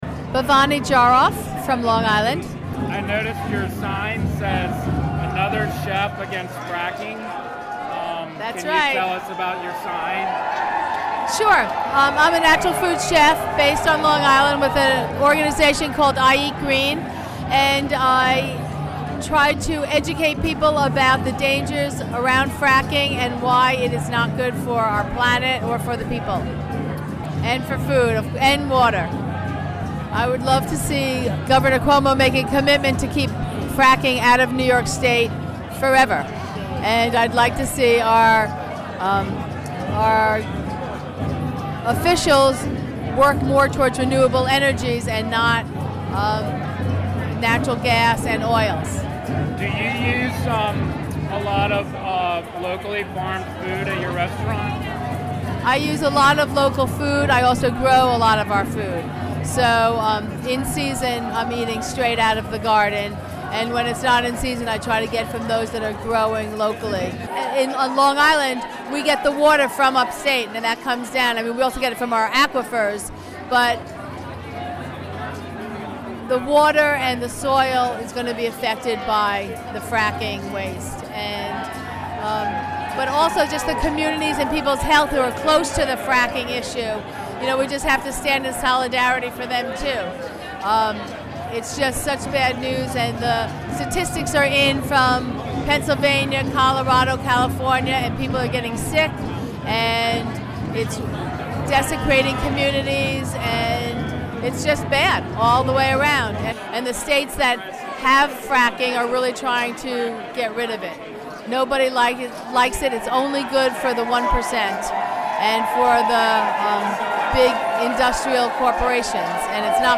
(2:05) Recorded at the State of the State, Empire State Plaza, Albany.